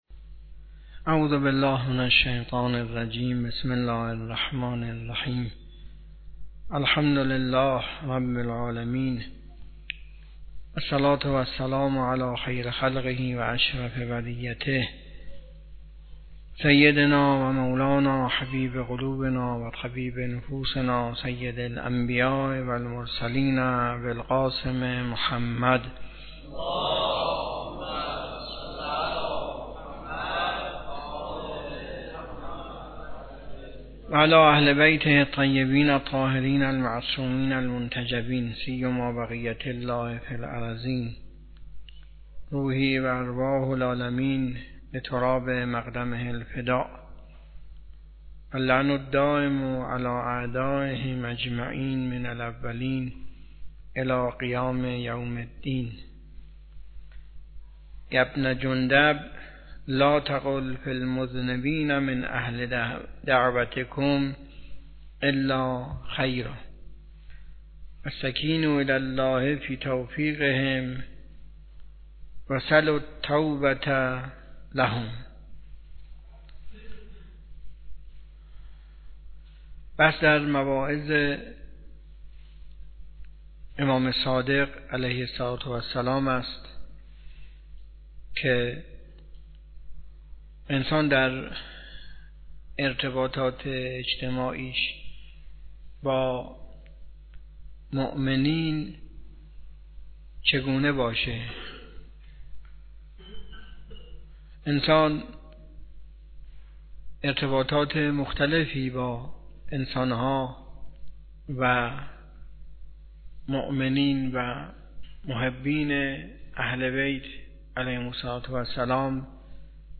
حوزه علمیه معیر تهران
درس اخلاق